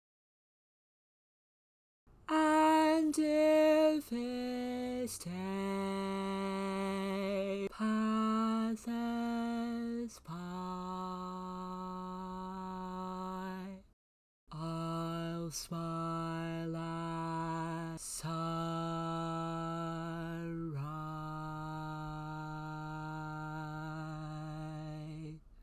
Key written in: B Major
Each recording below is single part only.